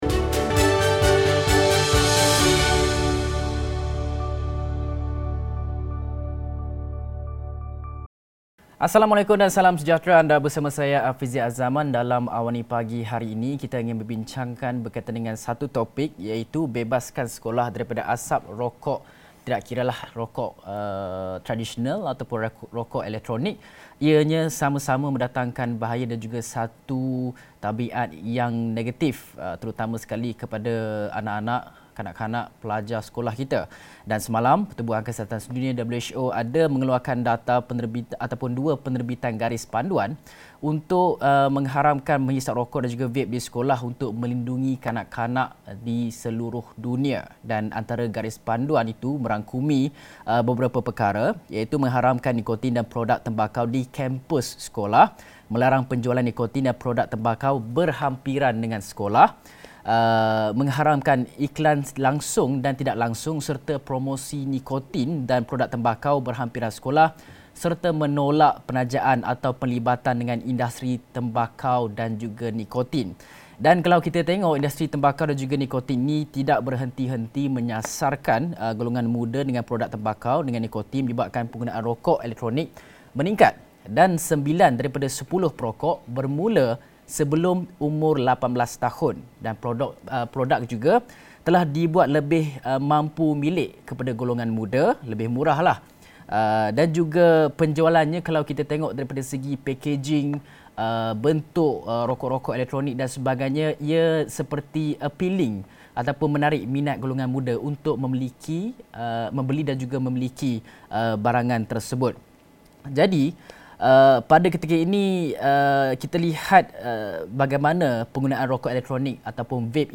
Diskusi 7.30 pagi bersama dua tetamu